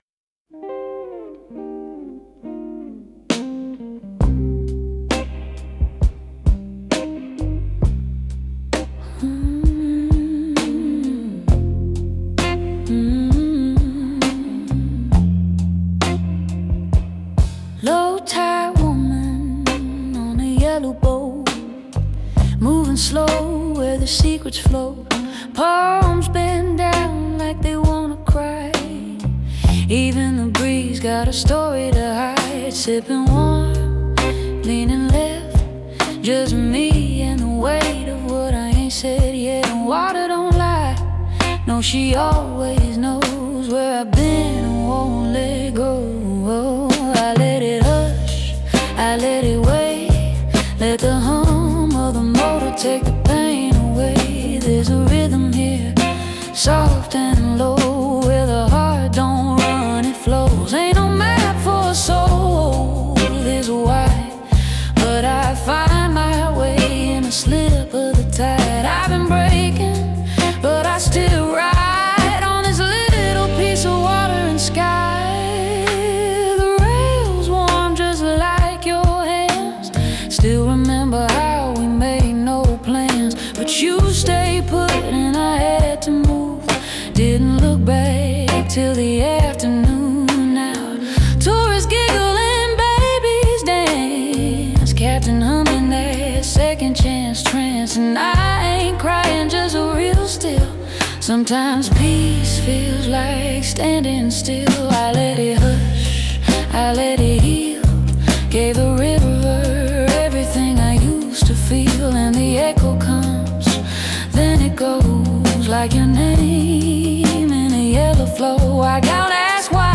Southern Tropic Rock and Blues Music Creation